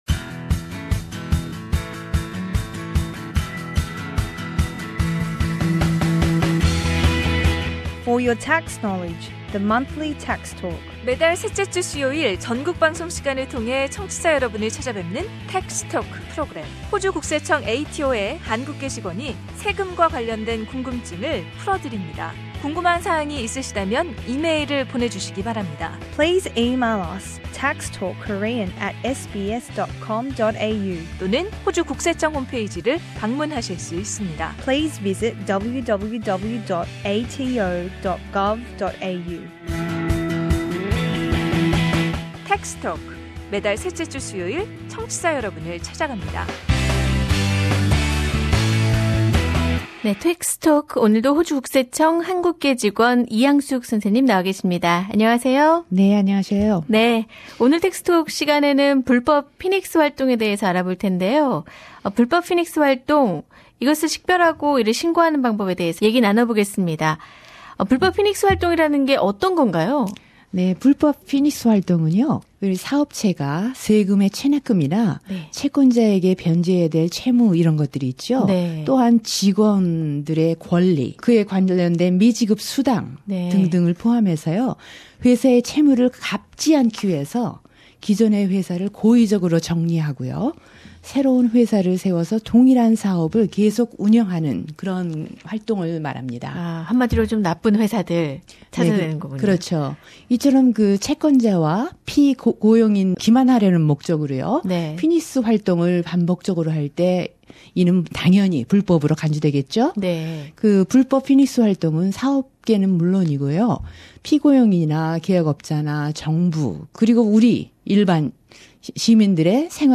The following community information is brought to you by the Australian Taxation Office. Interview